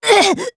Xerah-Vox_Damage_Madness_01_jp.wav